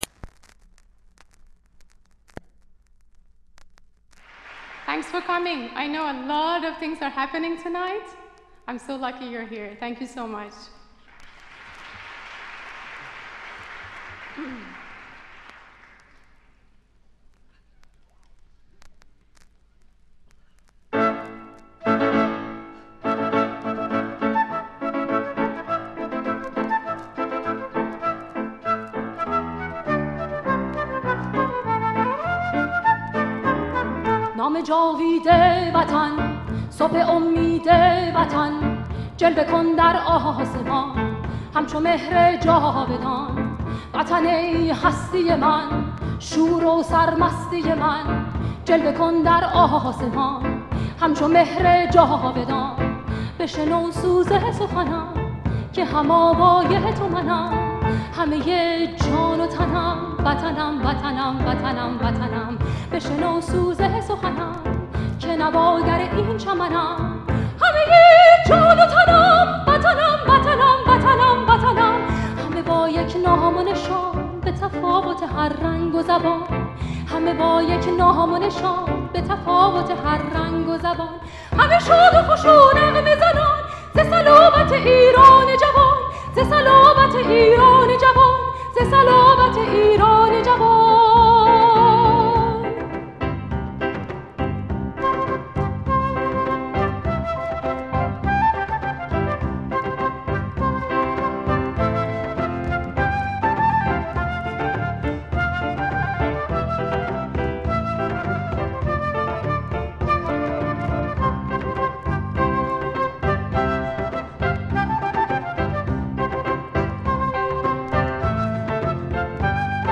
ژانر: پاپ
🎤 خواننده : با صدای زن اینستاگرام ۲